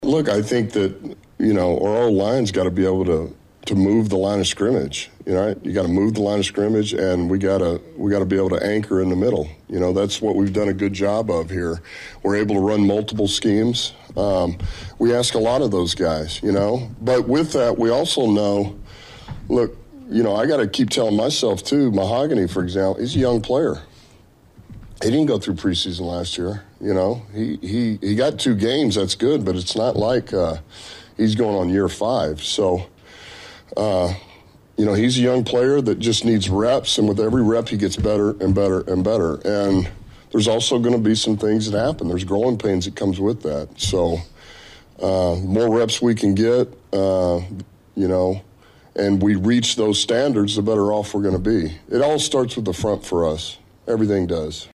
Head Coach Dan Campbell took to the podium Wednesday and talked about the importance of the offensive line…